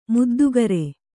♪ muddugare